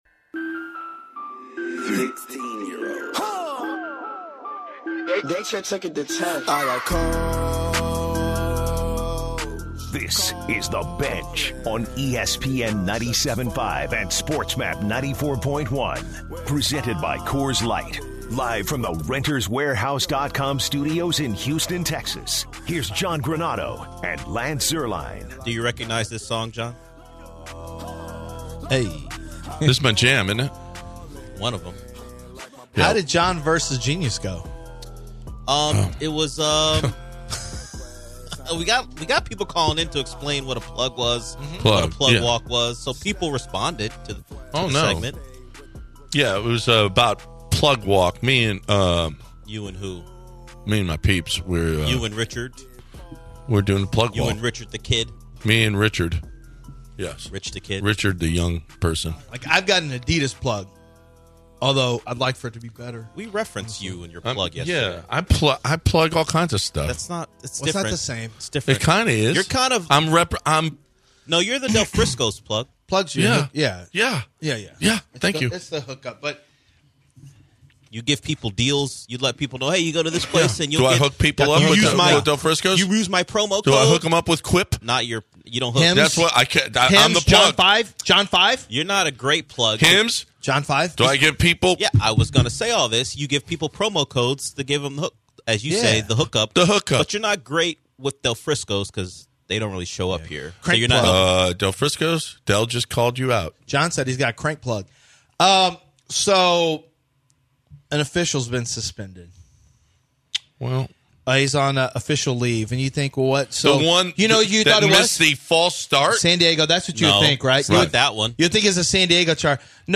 The guys take calls about the subject and discuss which of the Houston franchises went out of their way to hire “high character” guys. To wrap up the second hour the guys do their What are the Odds Wednesday segment.